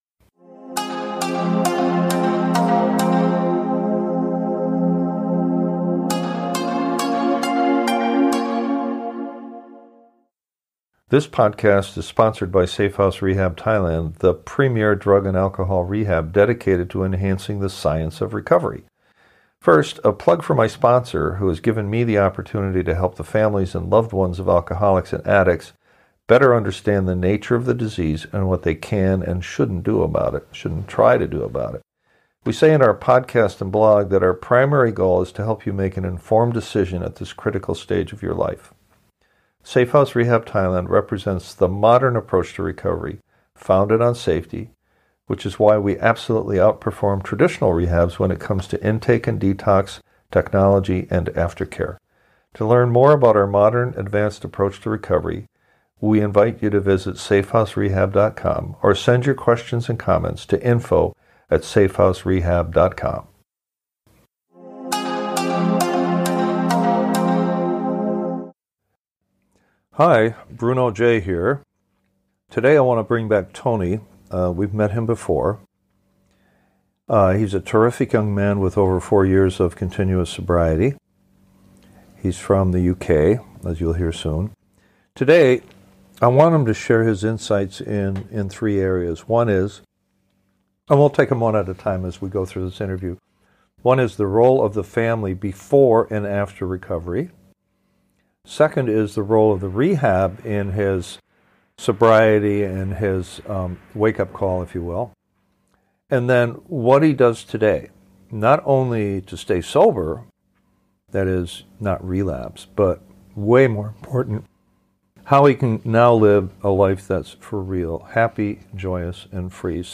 This interview